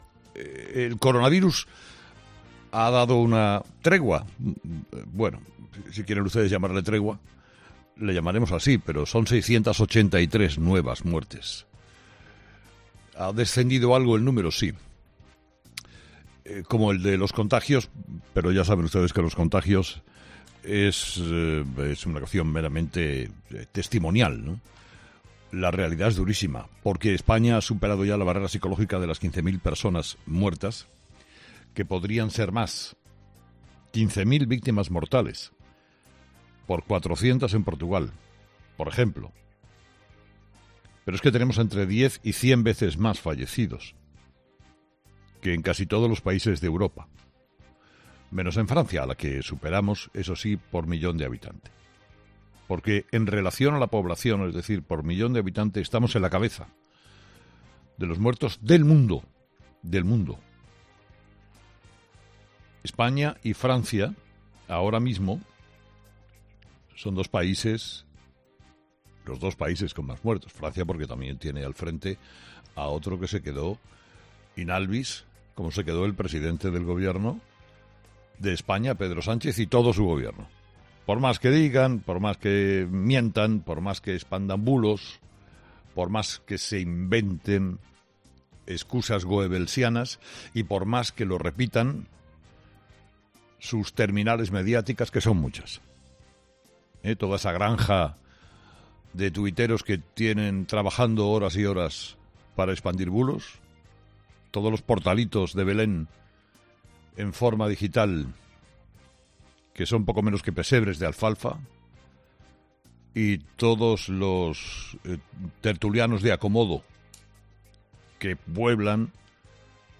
El propio Carlos Herrera hablaba sobre él en su monólogo de este Viernes Santo. Para el comunicador, el reportaje de Sky News muestra la realidad del coronavirus en España que no nos están enseñando las televisiones nacionales.